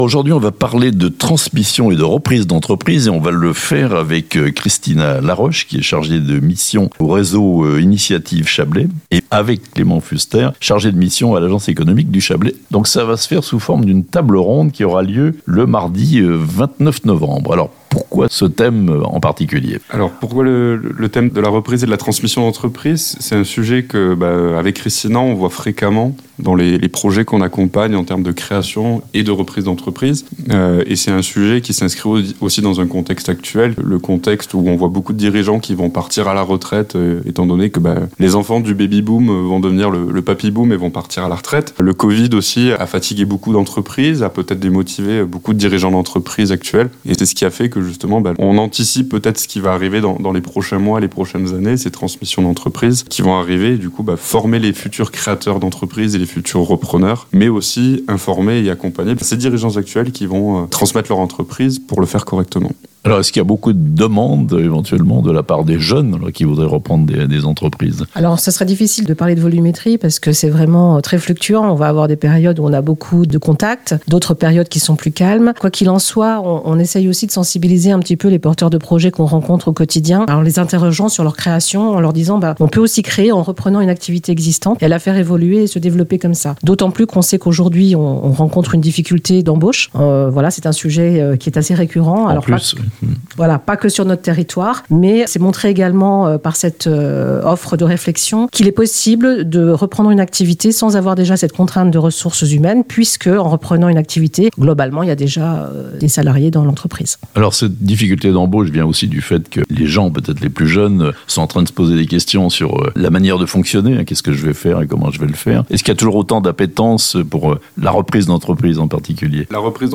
La transmission-reprise d'entreprise au menu de la prochaine session de "Créa'Lac" à Thonon le 29 novembre (interviews)